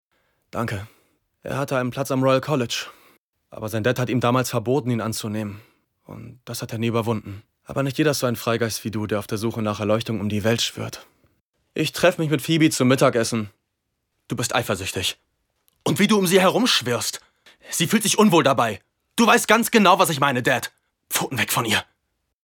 sehr variabel, hell, fein, zart
Jung (18-30)
Lip-Sync (Synchron)